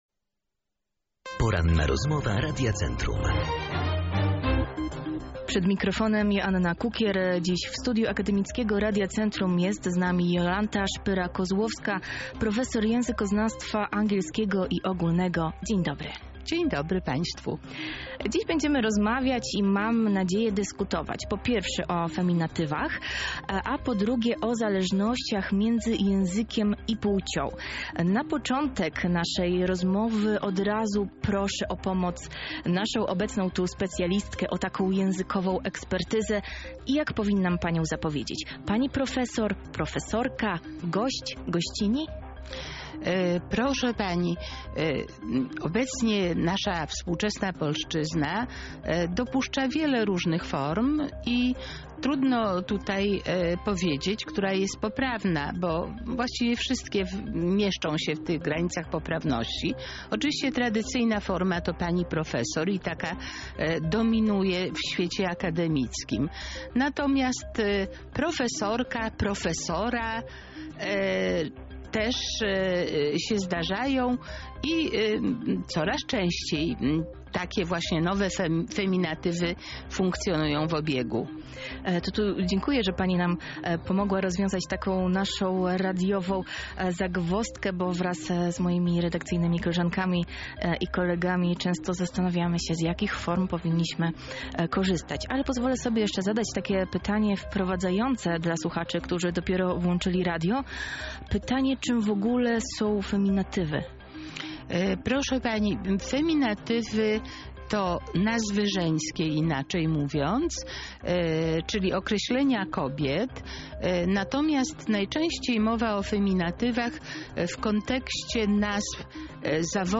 Poniżej cała rozmowa: